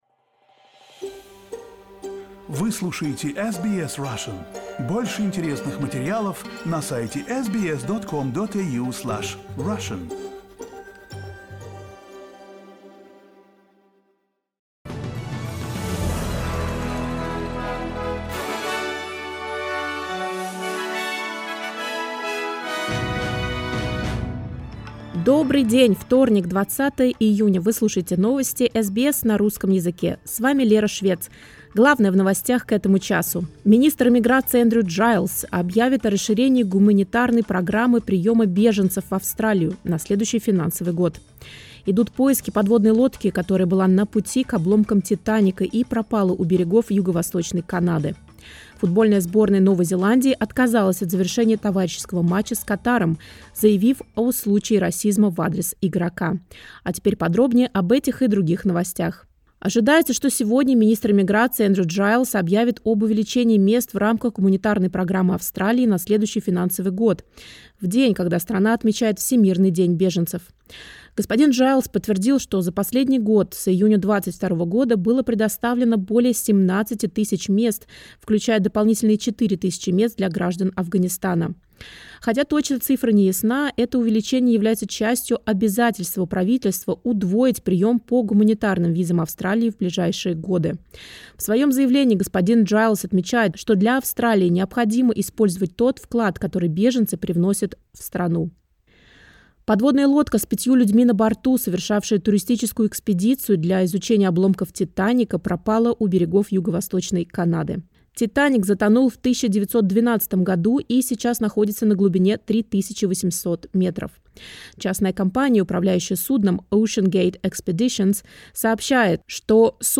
SBS news in Russian — 20.06.2023